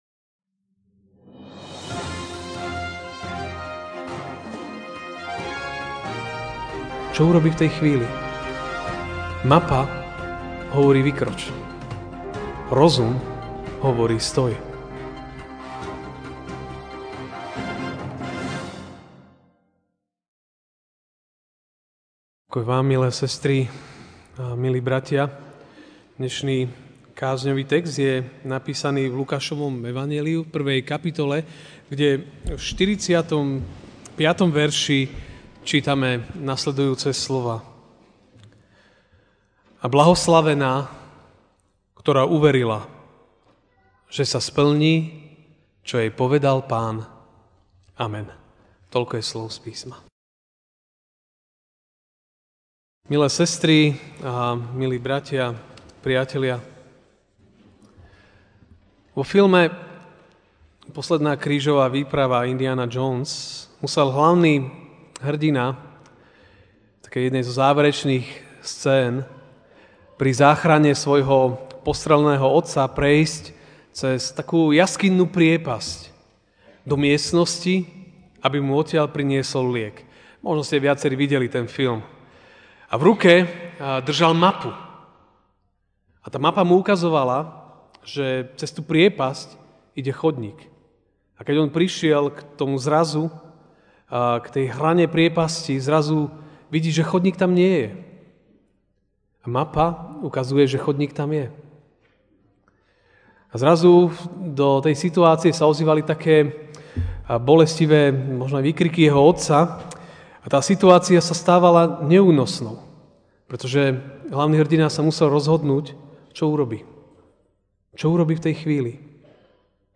Štedrovečerné SB: Dilema Indiana Jonesa (Lukáš 1, 45) A blahoslavená, ktorá uverila, že sa splní, čo jej povedal Pán.